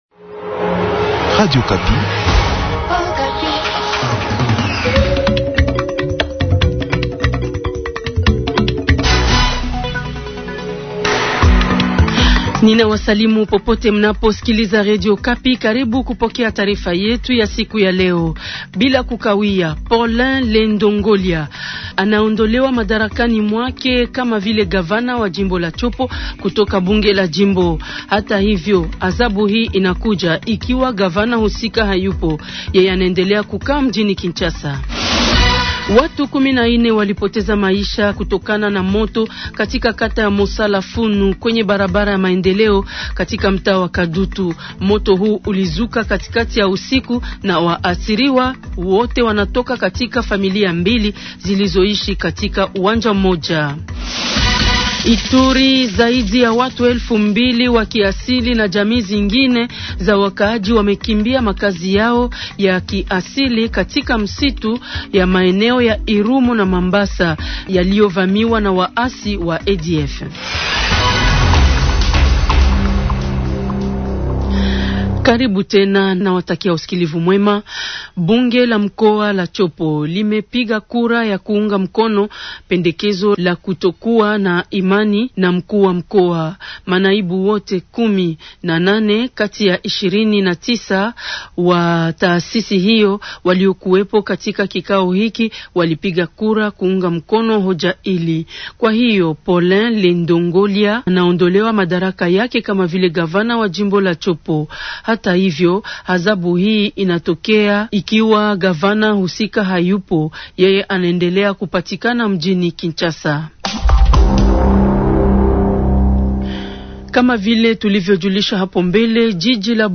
journal soir
Journal Swahili du Lundi SOIR le 27 octobre 2025.